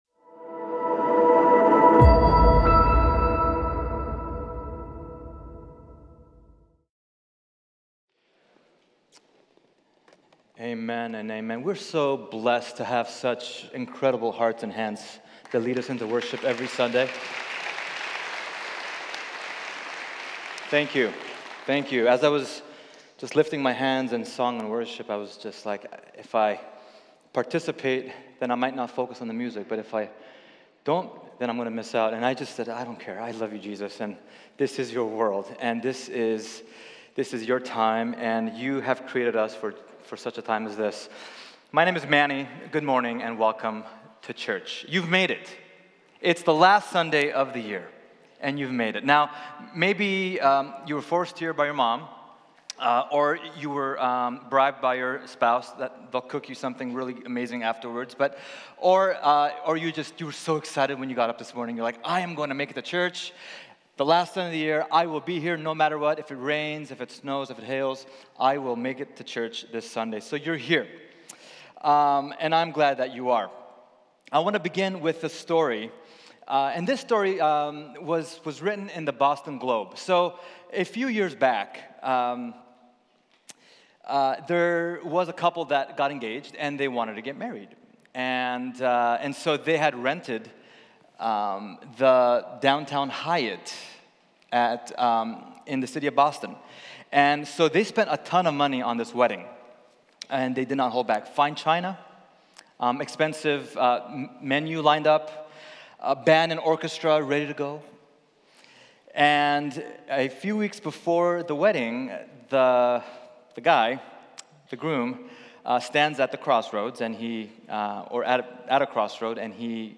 Choose Your Story New Year's Eve Service New Year's Eve Sermon Listen Now Joshua 24